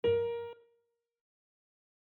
ASharp_LADiese.mp3